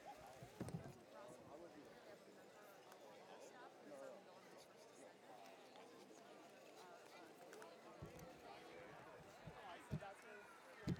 Menominee POWWOW